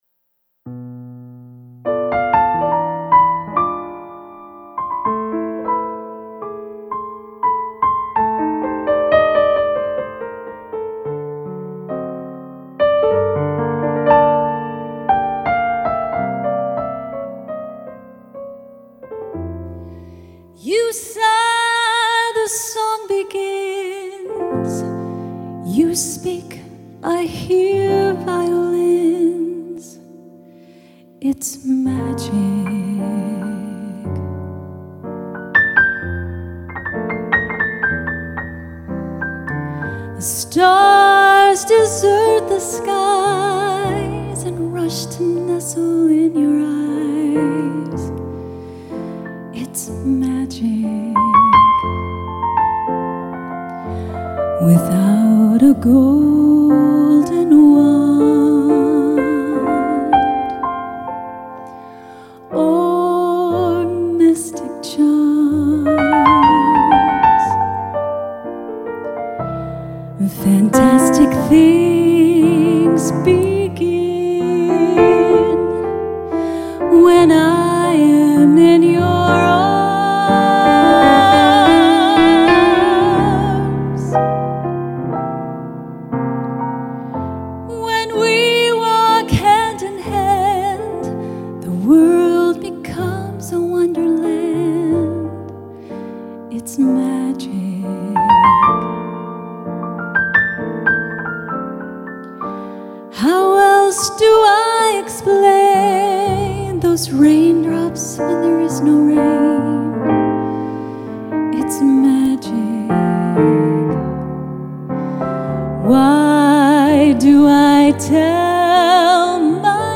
LIVE
pianist